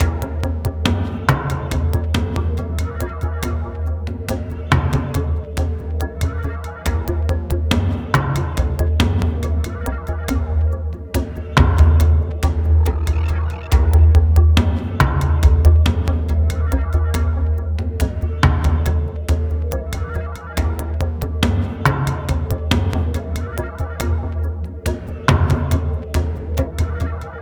Downtempo 23.wav